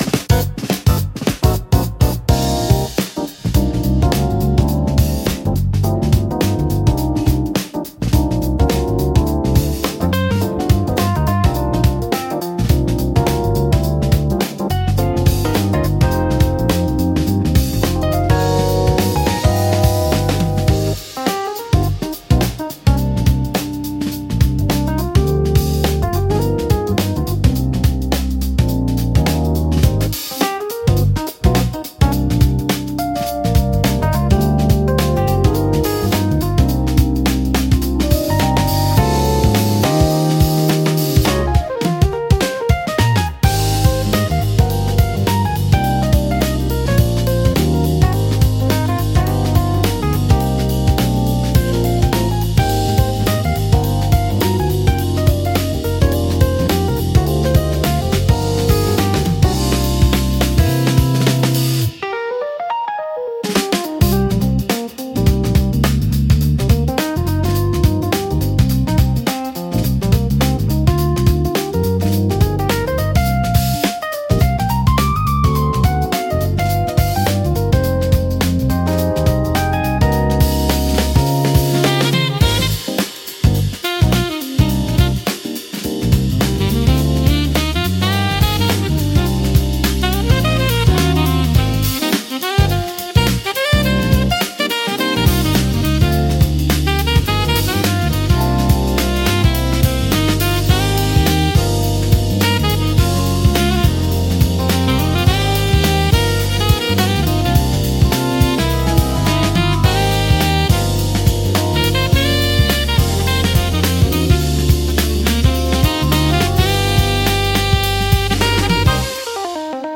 落ち着いた空気感を作り出しつつも、聴き疲れしにくい快適さがあり、ゆったりとした時間を楽しみたいシーンで多く活用されます。